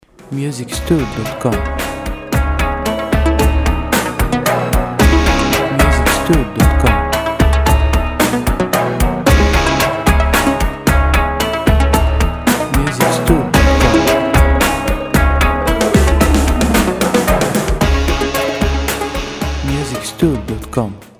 • Type : Instrumental / Audio Track
• Lyrics : No
• Bpm : Allegretto
• Genre : Rock / Riff